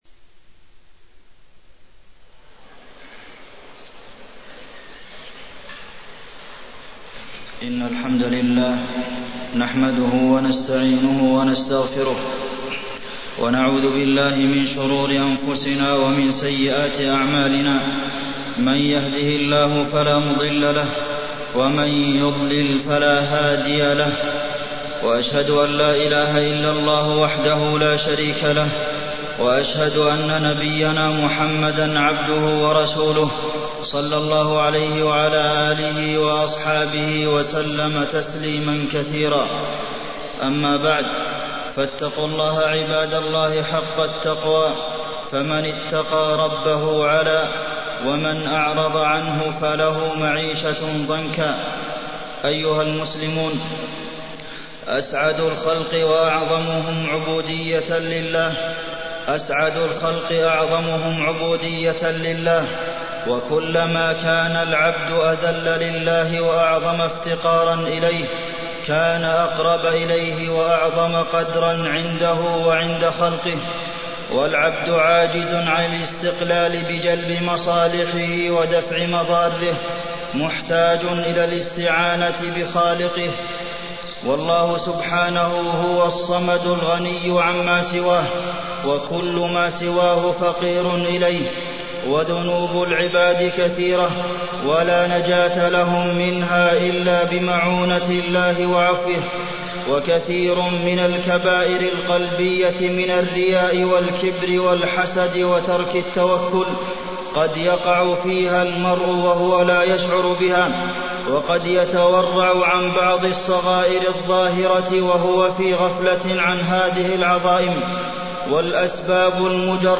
تاريخ النشر ١٠ جمادى الآخرة ١٤٢٤ هـ المكان: المسجد النبوي الشيخ: فضيلة الشيخ د. عبدالمحسن بن محمد القاسم فضيلة الشيخ د. عبدالمحسن بن محمد القاسم التوكل